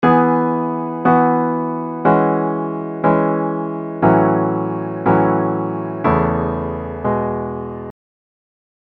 Key written in: E Major
How many parts: 5
Type: Other mixed
Comments: Nice gentle reassuring tag.
All Parts mix: